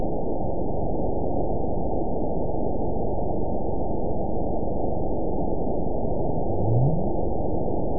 event 912254 date 03/22/22 time 06:30:44 GMT (3 years, 2 months ago) score 9.39 location TSS-AB01 detected by nrw target species NRW annotations +NRW Spectrogram: Frequency (kHz) vs. Time (s) audio not available .wav